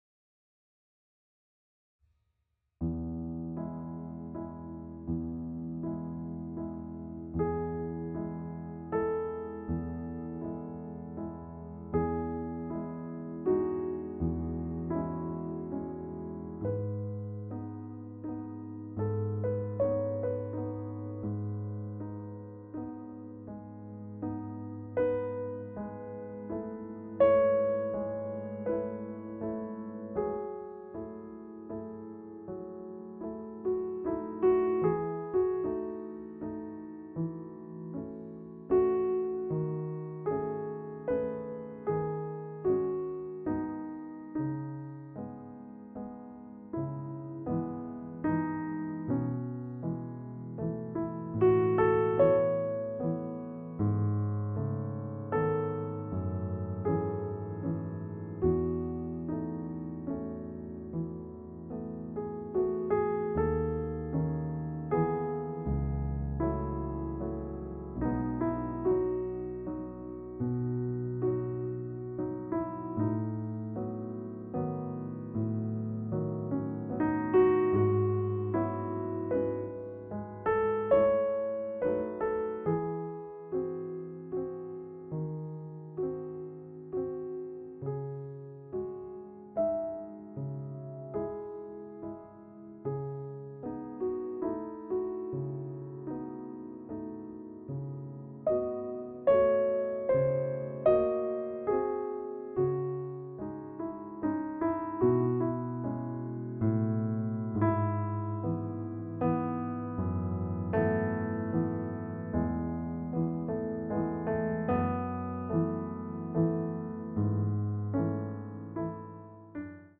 Pianoteq 7: YC5 Grand Piano